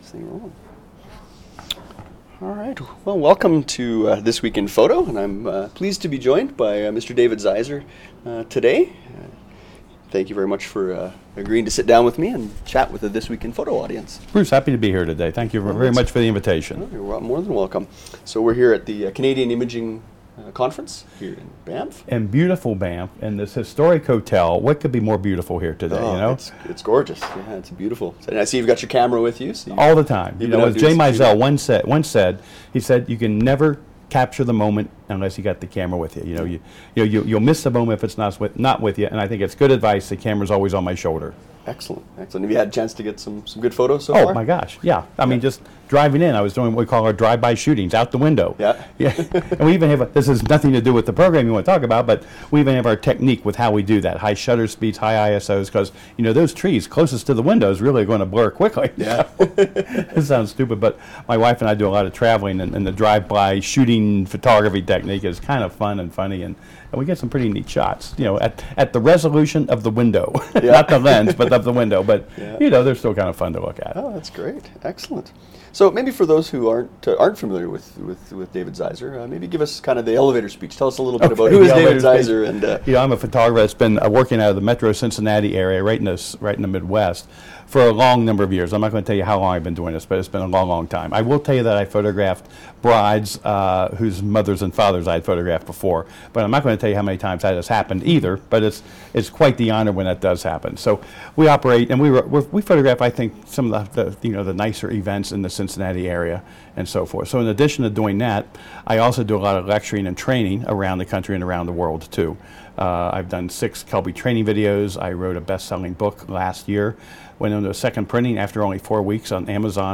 An Interview with Wedding Photographer